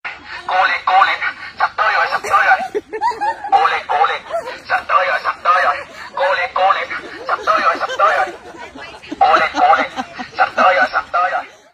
Thể loại: Câu nói Viral Việt Nam
Description: Hiệu ứng âm thanh Cố lên cố lên … sắp tới dòi, sắp tới dòi viral TikTok mp3 bản gốc từ video TikTok...